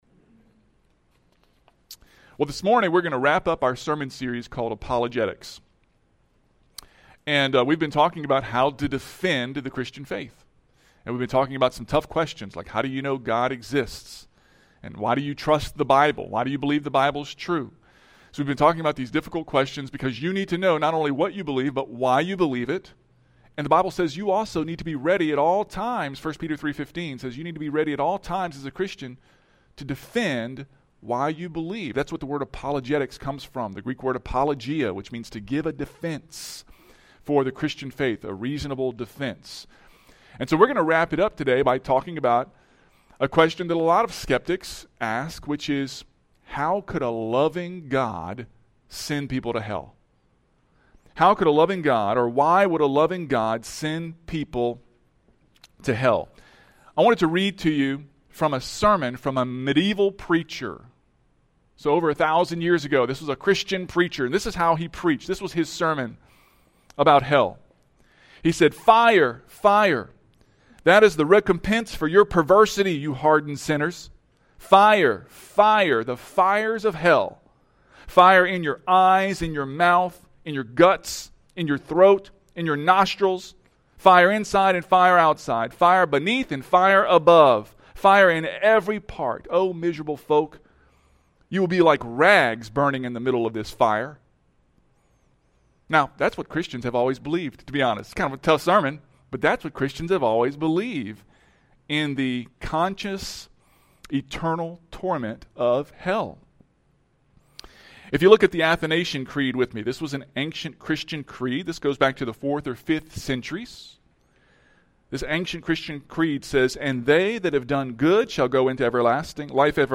February 9, 2025 Watch Listen Save Apologetics – Week 6 Discussion Guide Download Sermon Manuscript Download Audio (MP3) Previous Why Do You Believe The Bible is God's Word?